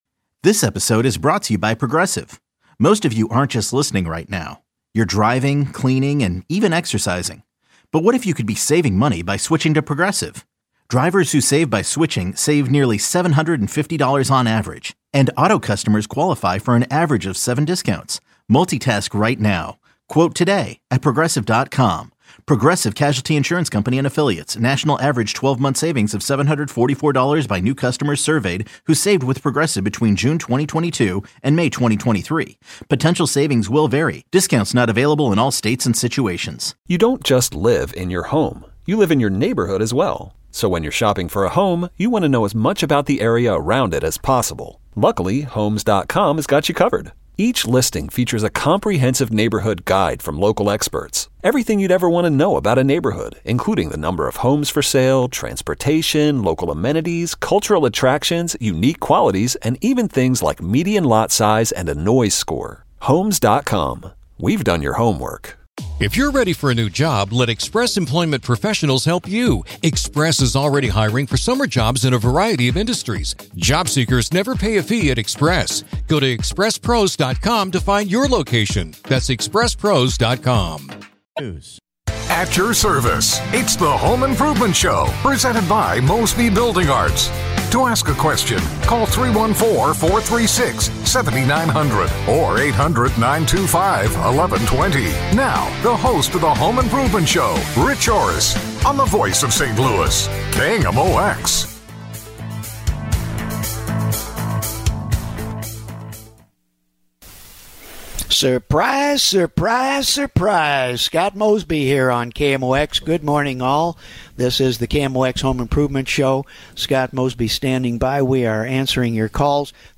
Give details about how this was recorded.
Throughout the episode, listeners are encouraged to call in with their pressing questions and home improvement dilemmas.